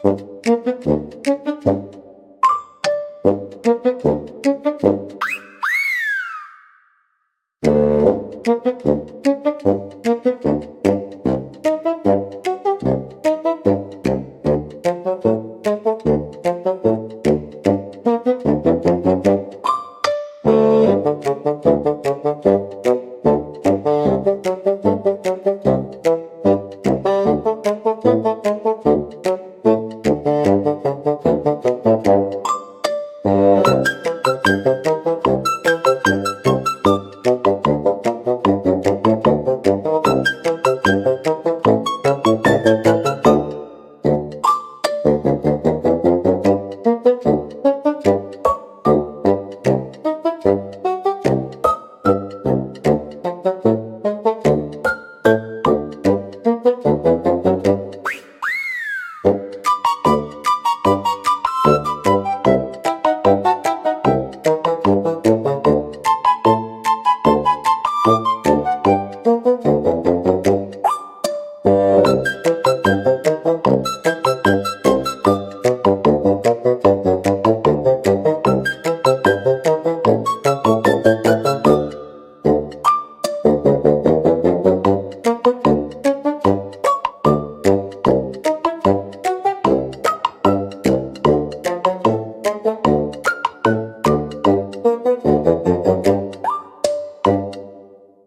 おとぼけは、バスーンとシロフォンを主体としたコミカルでドタバタした音楽ジャンルです。
視聴者の笑いを誘い、軽快で親しみやすいムードを演出しながら、退屈を吹き飛ばします。